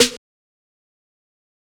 kits/OZ/Snares/Sn (Dont).wav at ts